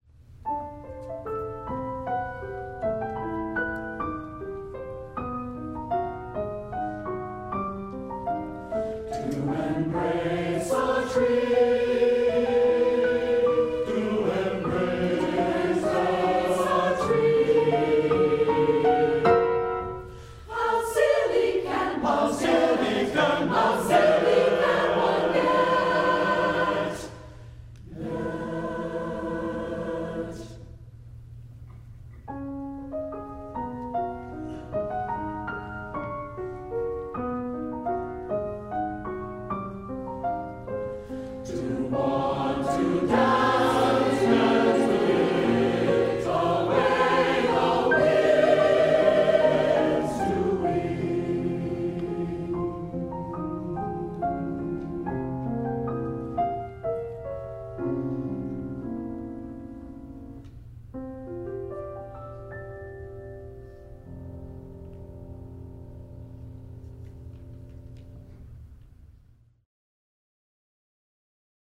Eight Zen-like poems for SATB (and piano in some of them.)